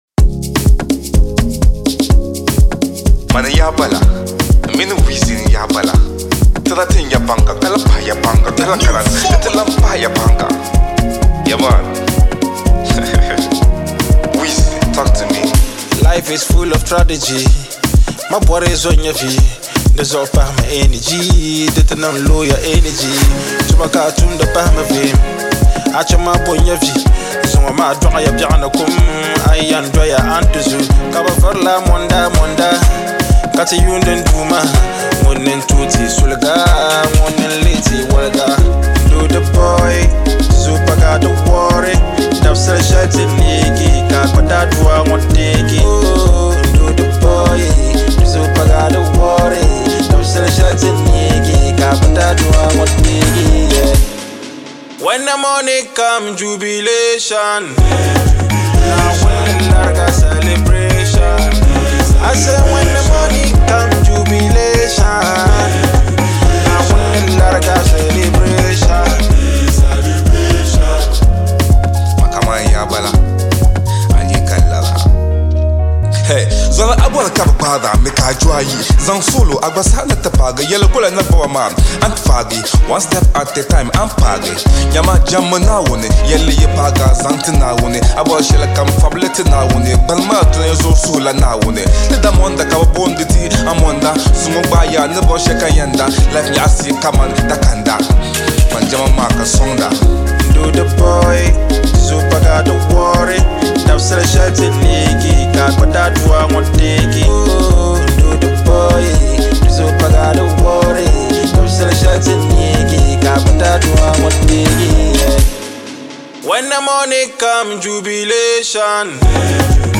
• Genre: Afrobeat